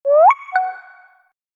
Bubble.ogg